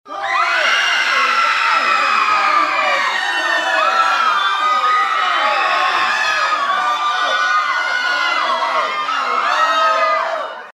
Звуки паники
В коллекции представлены тревожные крики, хаотичные шаги, гул взволнованной толпы и другие эффекты для создания напряженной атмосферы.
Звук панической толпы